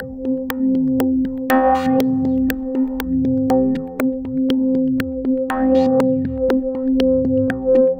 Ew Perc String.wav